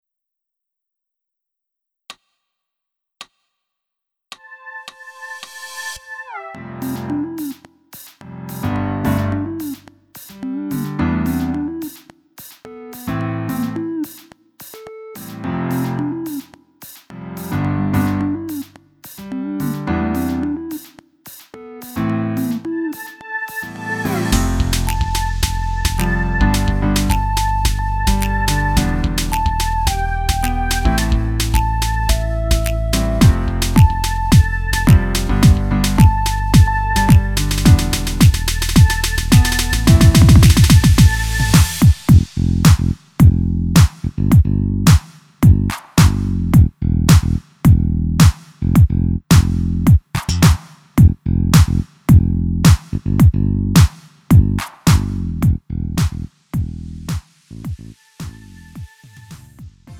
음정 원키 3:08
장르 구분 Lite MR